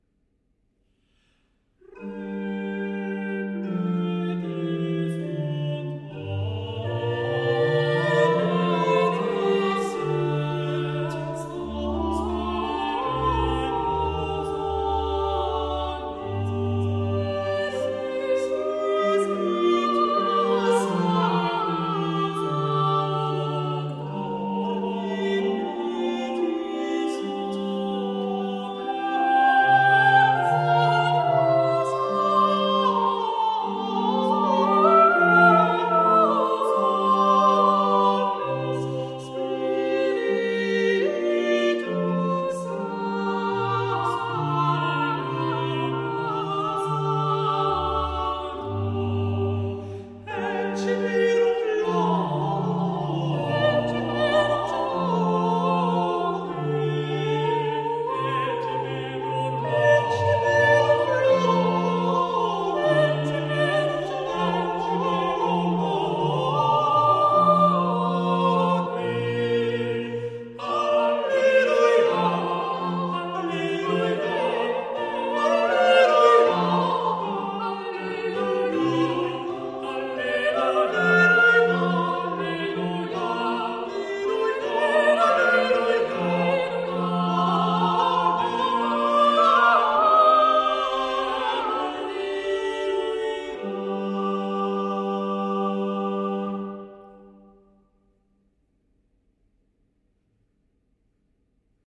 Polyphonic of 3 voices, where several voices sing words.
motet a trois voix et continuo